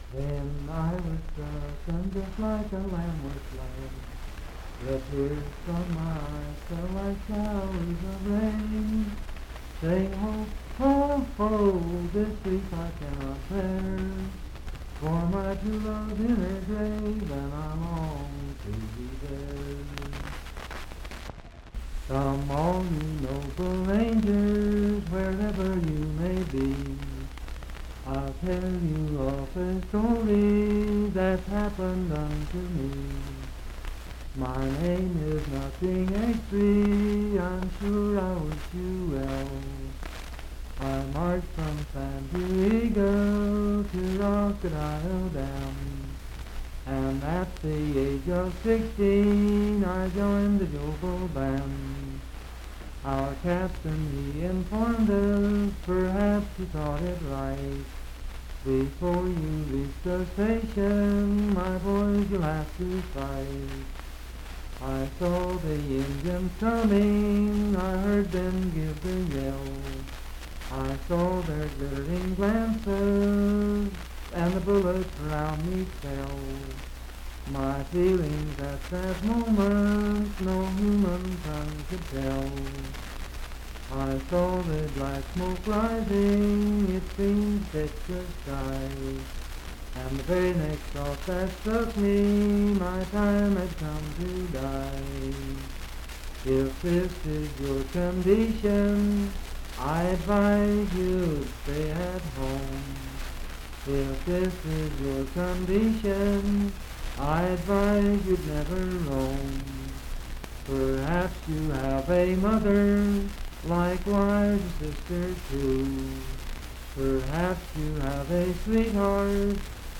Unaccompanied vocal music
Voice (sung)
Pocahontas County (W. Va.), Marlinton (W. Va.)